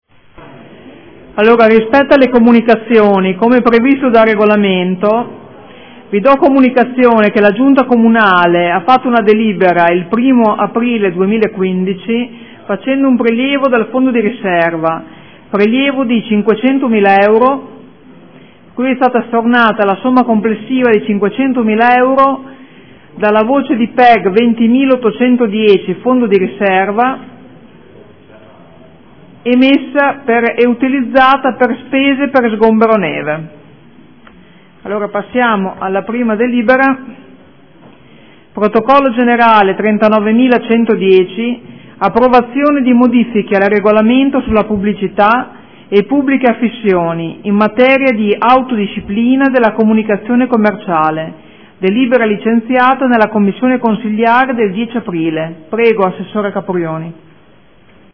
Seduta del 22 aprile. Comunicazione del Presidente su di una delibera di giunta del 1° aprile 2015